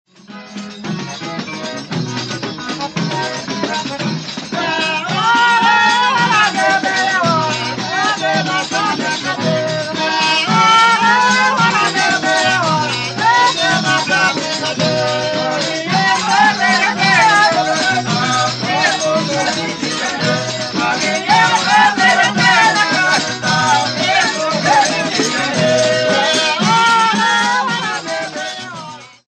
Reis-de-boi
Na apresentação é tocada marcha para a chamada do vaqueiro, que vem sapateando, batendo o ritmo com bastão.
E finaliza-se com o sanfoneiro puxando o canto da divisão do boi, com o coro cantando refrão, a cada pedaço vendido.
autor: Grupo Reis-de-boi de Conceição da Barra, data: 1980